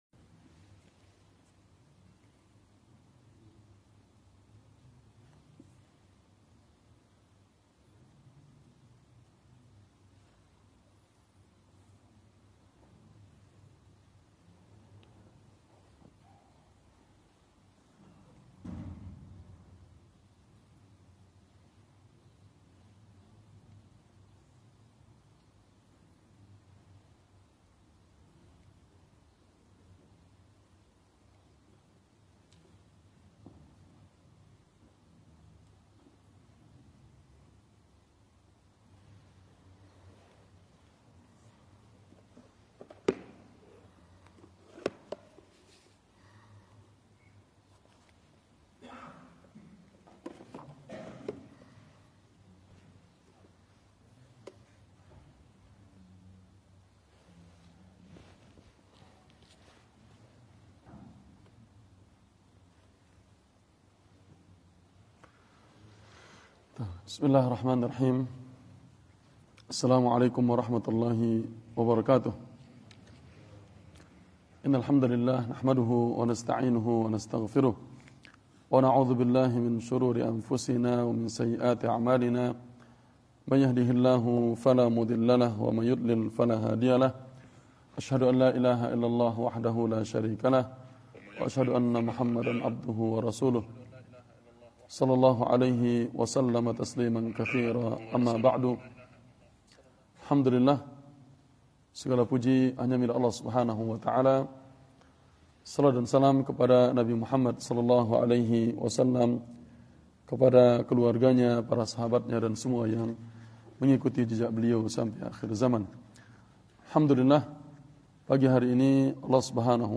Daurah Al-Khor Sabtu Pagi – Masjid At-Tauhid Syarah Riyadhus Shalihin Bab 46 – 5